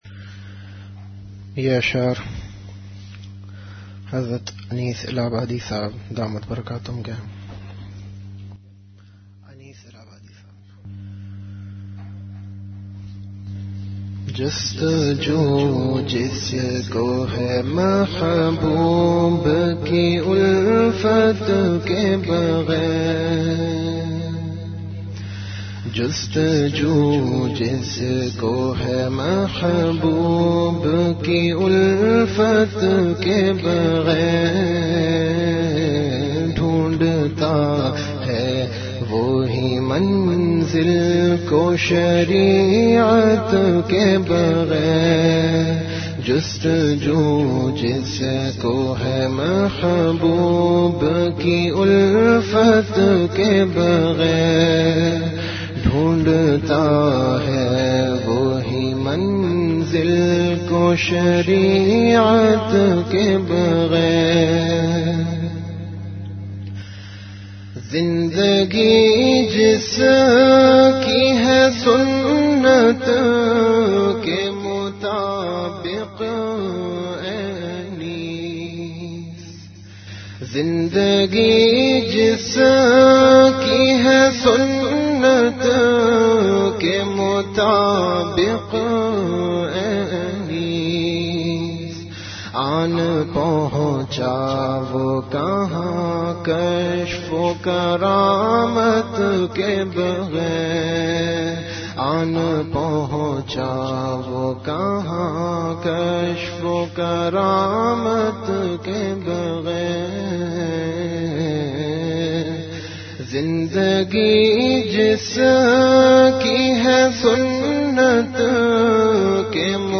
Delivered at Home.
Majlis-e-Zikr · Home Sunnat Pe Istiqaamat